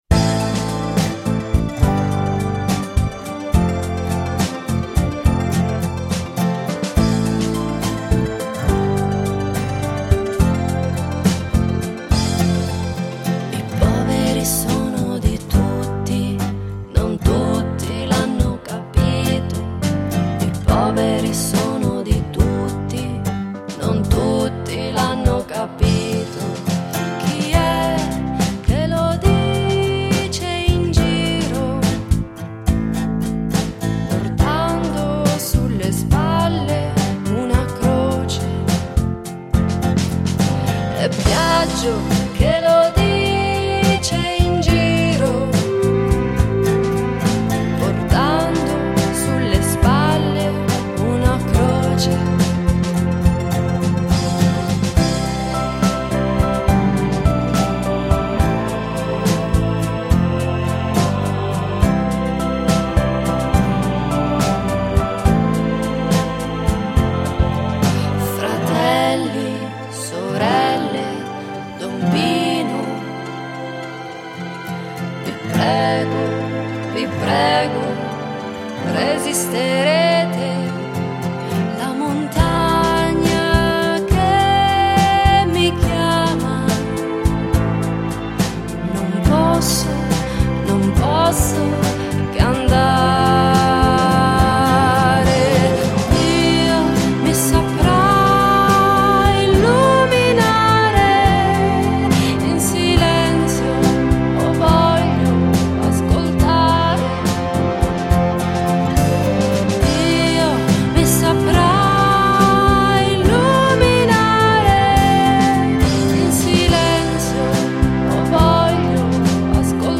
Una canzone per Biagio Conte
pianoforte e voce
chitarre